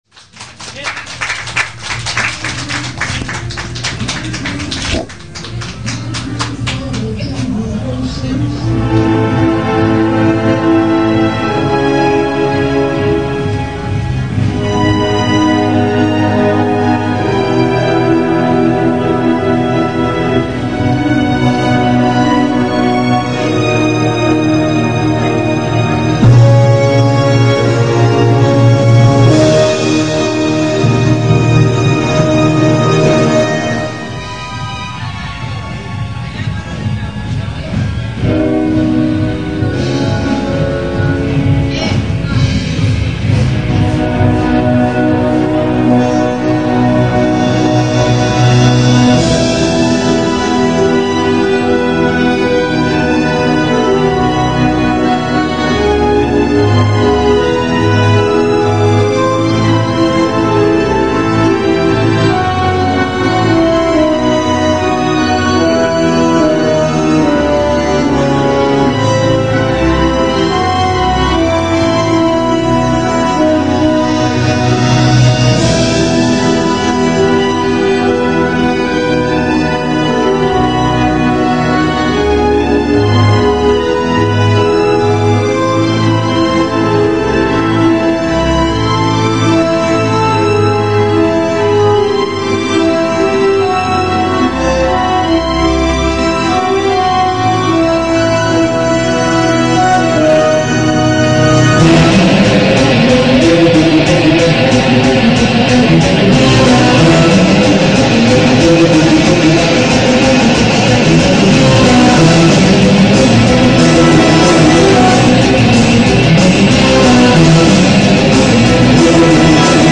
（東京大学駒場祭2004　ＳＨＫライブより）
アドリブではなく原曲に忠実に弾きました。
ww4_live.mp3